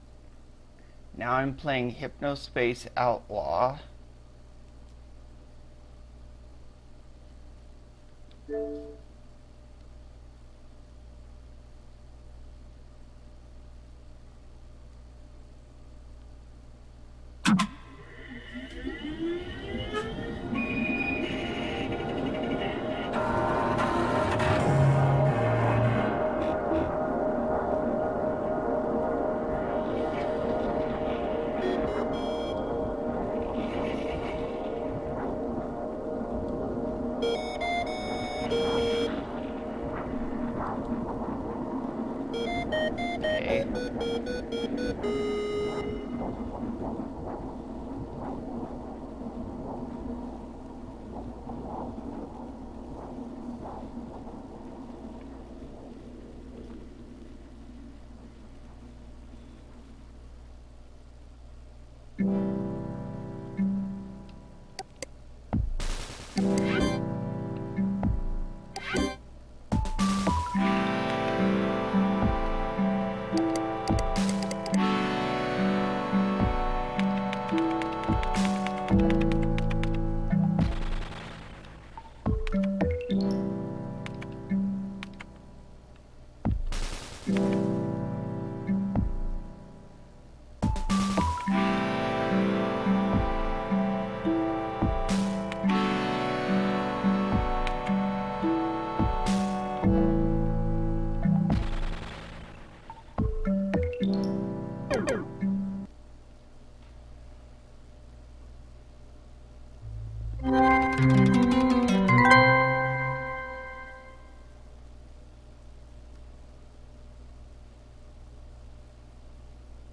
I play Hypnospace Outlaw with commentary